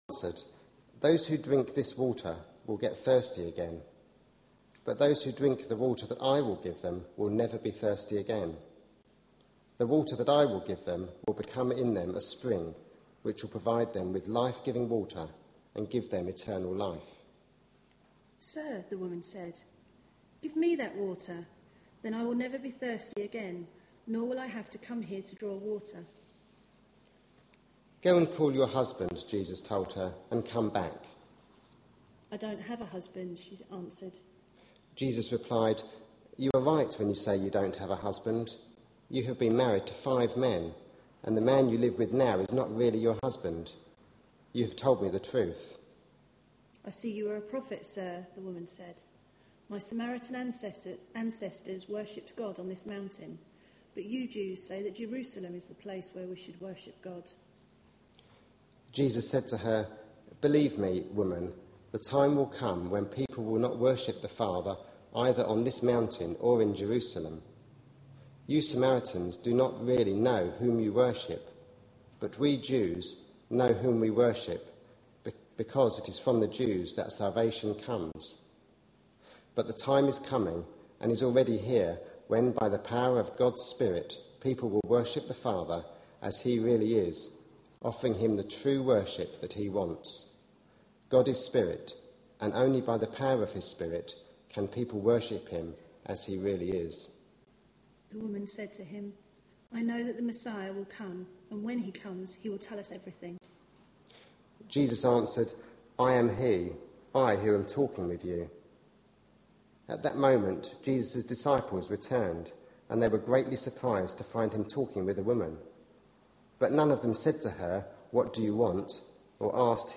A sermon preached on 19th February, 2012, as part of our Looking For Love (6pm Series) series.